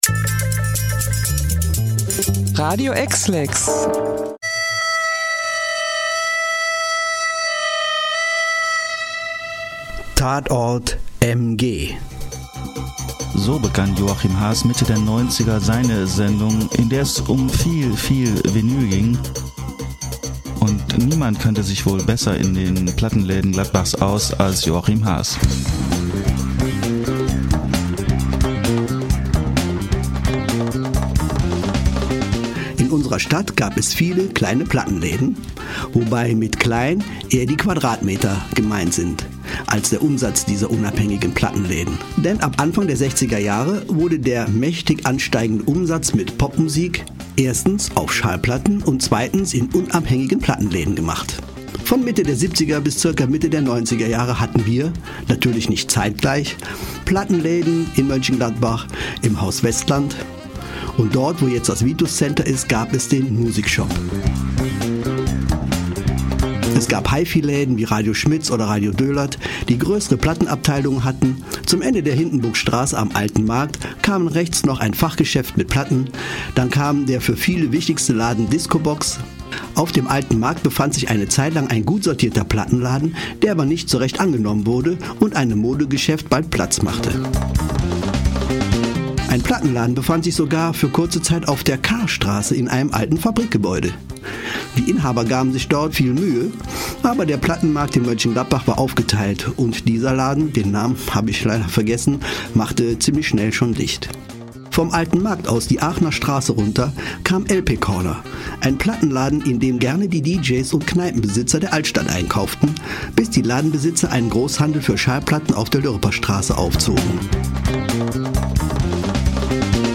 Als Einstimmung zum Thema gibt es daher hier einen kurzen Bericht über die Plattenläden in Gladbach Anfang der 90er – viel Spaß beim Ent- oder Wiederentdecken.